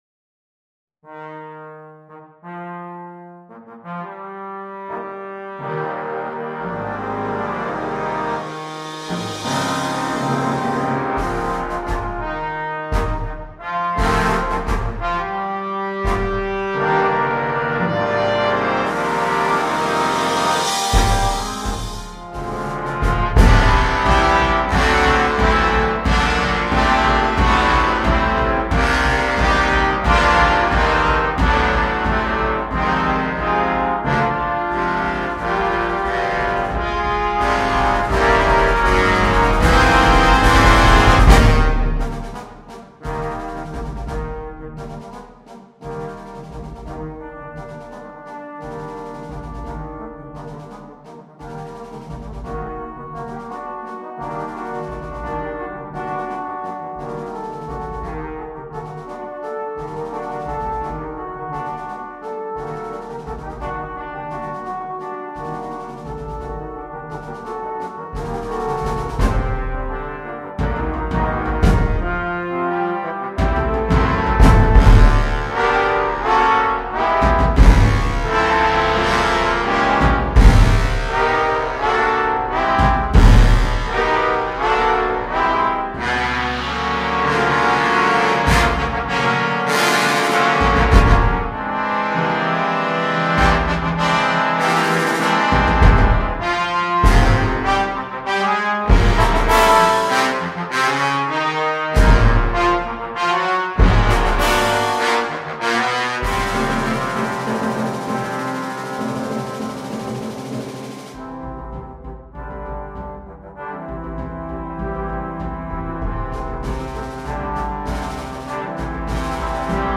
per doppio quartetto di tromboni e percussioni
midi